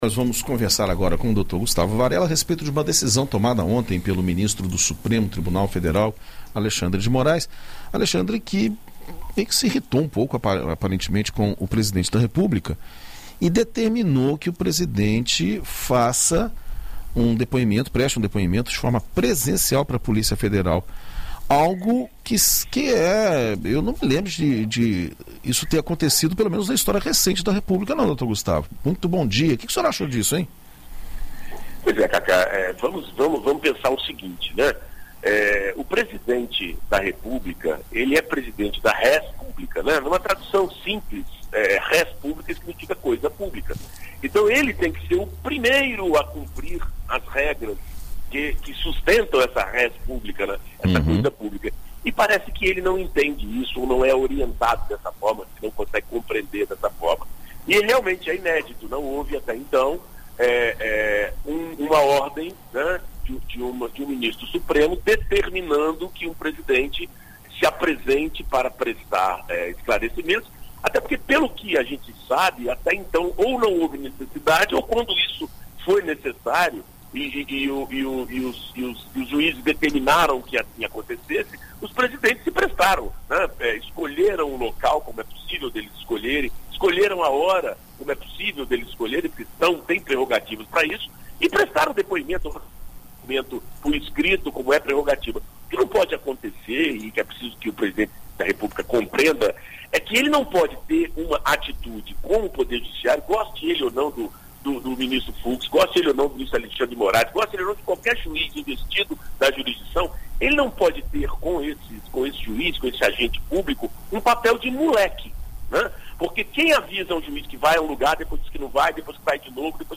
Na coluna Direito para Todos desta sexta-feira (28), na BandNews FM Espírito Santo,